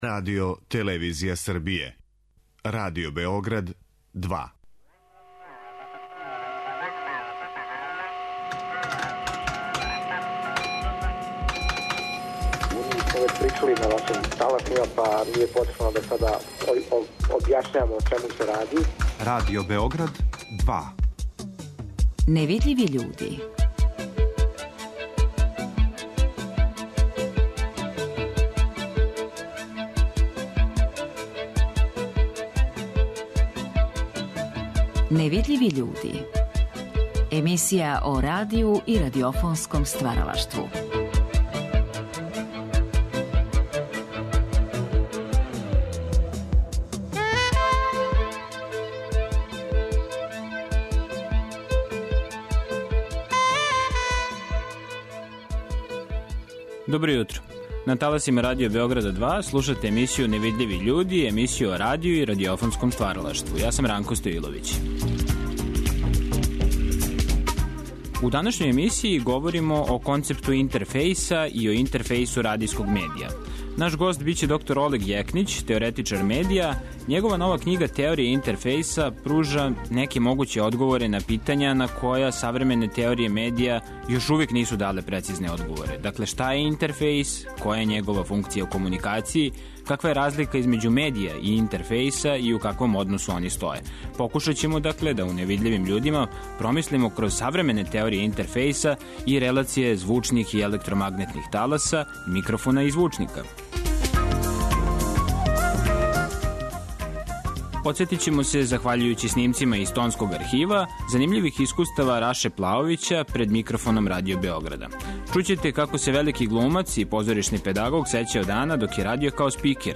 Подсетићемо се, захваљујући снимцима из Тонског архива, занимљивих искустава Раше Плаовића пред микрофоном Радио Београда.